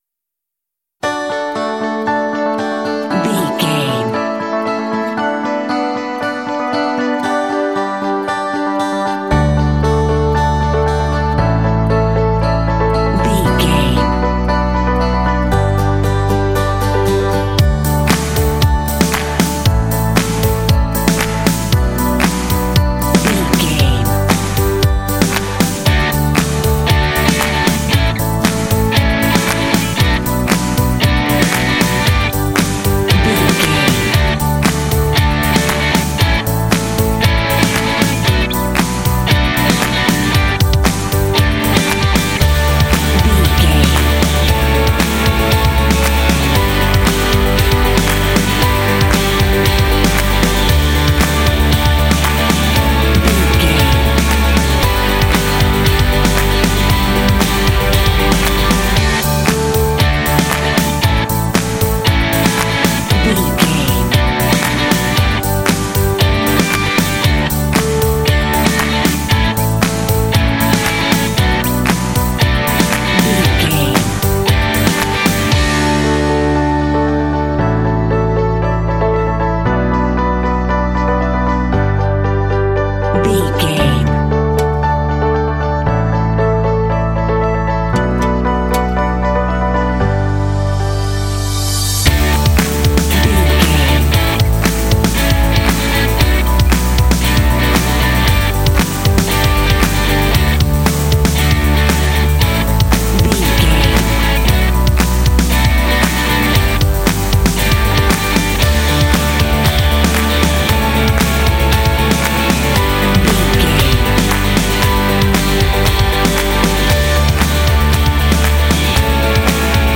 Uplifting
Ionian/Major
D
optimistic
happy
piano
acoustic guitar
electric guitar
bass guitar
rock
contemporary underscore
indie